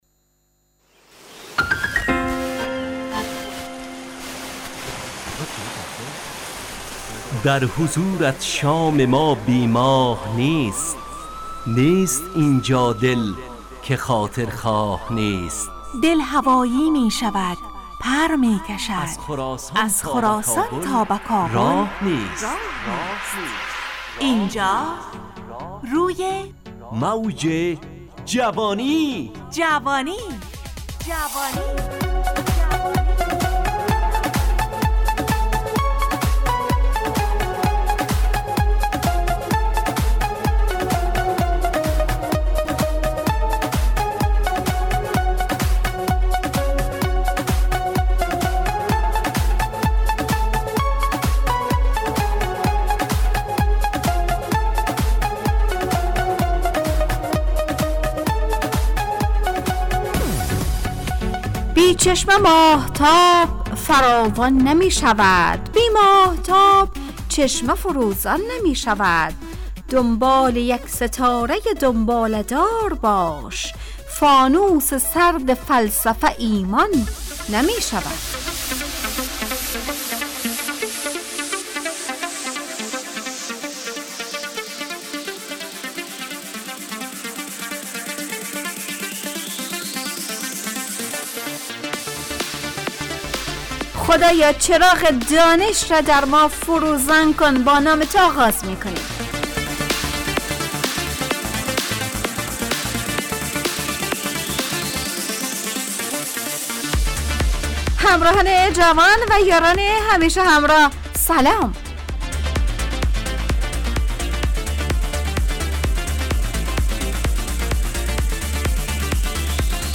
روی موج جوانی، برنامه شادو عصرانه رادیودری.
همراه با ترانه و موسیقی مدت برنامه 70 دقیقه . بحث محوری این هفته (انسانیت) تهیه کننده